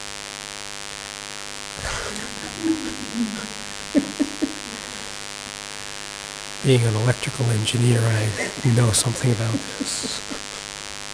This is spoken word. using Audacity 2.1.2 on macbook pro running 10.12 Sierra.
sorry, I wasn’t aware. 10 secs of just the noise and 5 secs with some voice.
It’s a real-bad case of mains-hum, where the fundamental frequency is 50.68Hz, (it’s usually closer to 50Hz),
and you only have the odd-numbered harmonics of 50.68Hz.